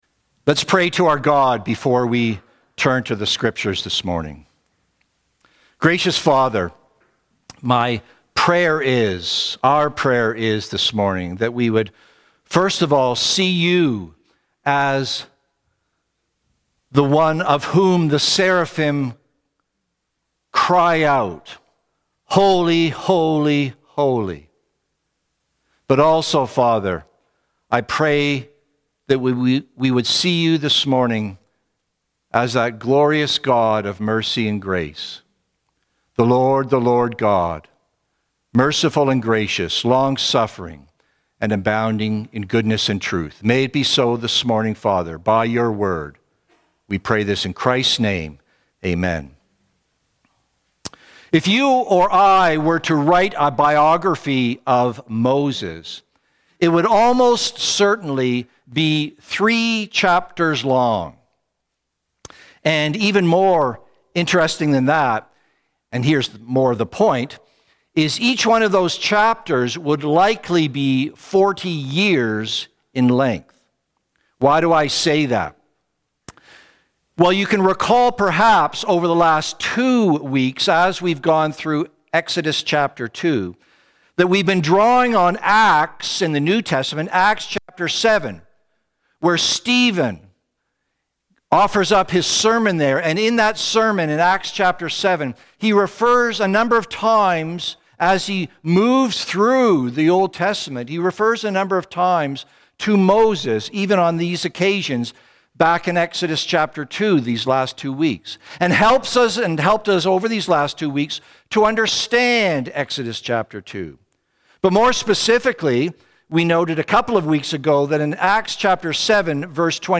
Sermons | Cranbrook Fellowship Baptist